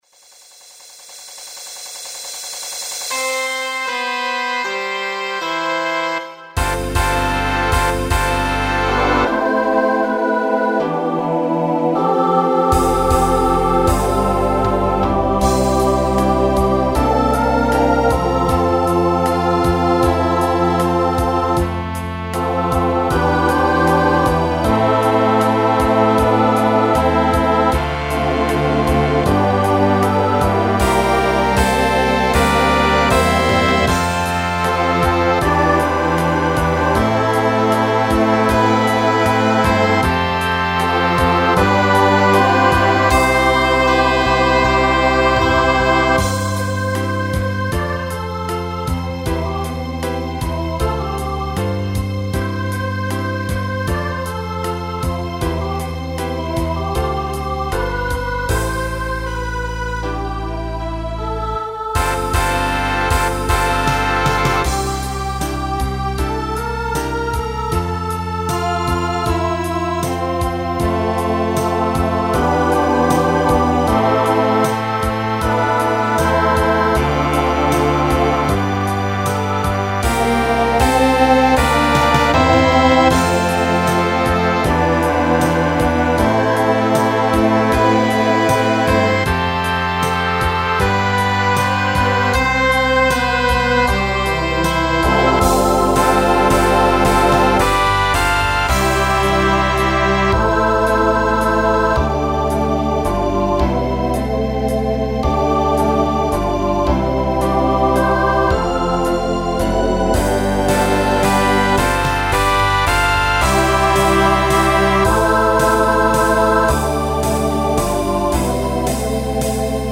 1970s Instrumental combo Genre Broadway/Film , Pop/Dance
Show Function Ballad Voicing SATB